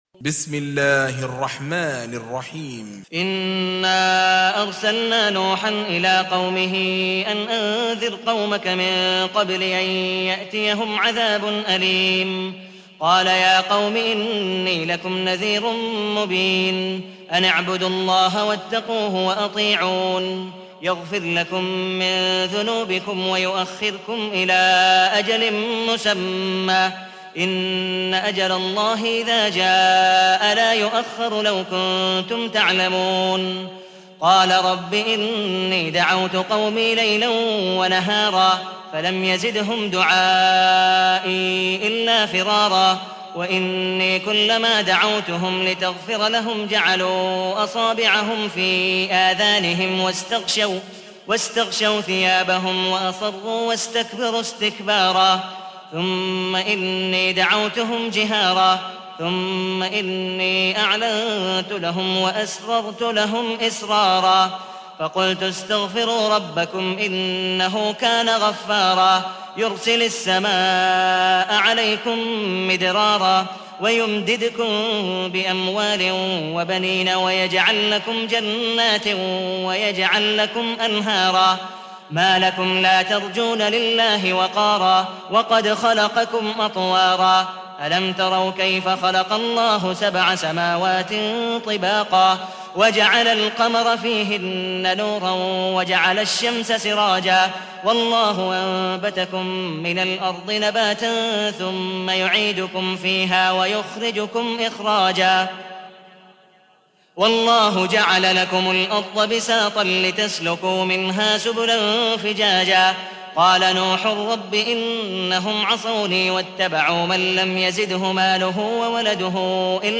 تحميل : 71. سورة نوح / القارئ عبد الودود مقبول حنيف / القرآن الكريم / موقع يا حسين